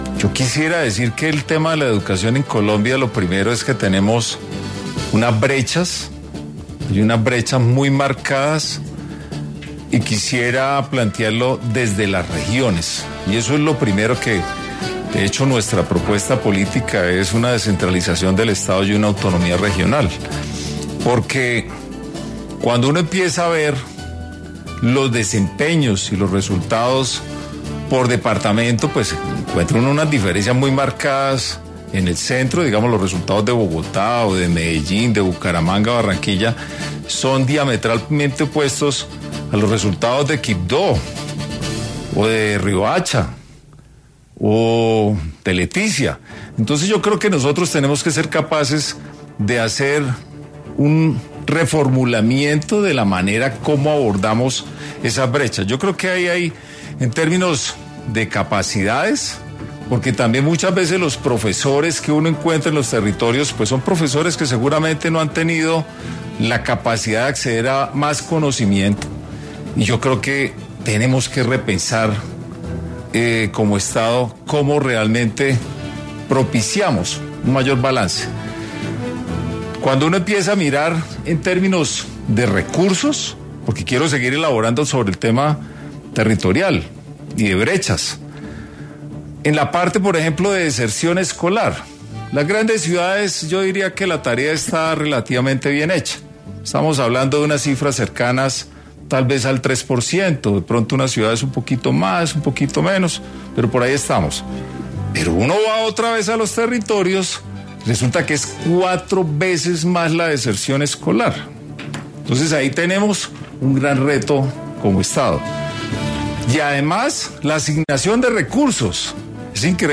El precandidato presidencial y exalcalde de Bucaramanga habló en Hora 20 sobre sus propuestas, relacionada con educación.